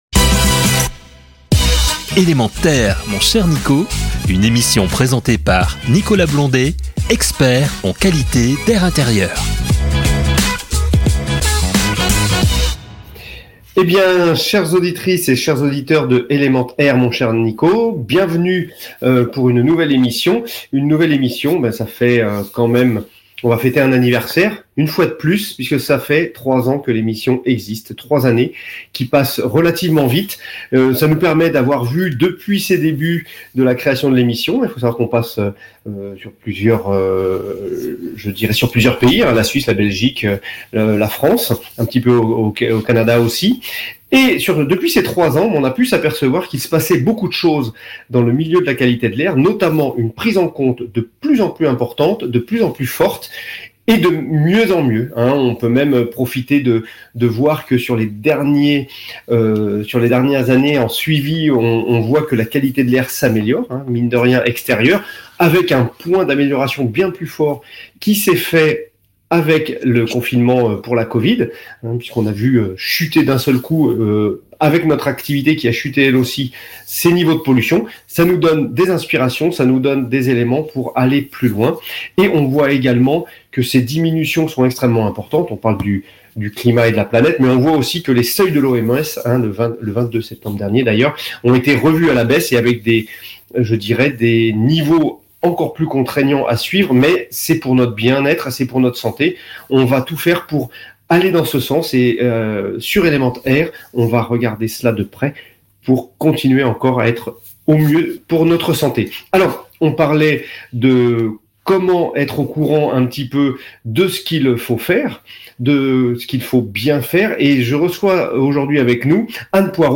La FPI publie les chiffres de l’année 2016 de son observatoire statistique national, baromètre des indicateurs avancés en logement dans toutes les régions de France. Entretien